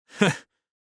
Звуки мужского смеха
Мужской смех такой вариант хаха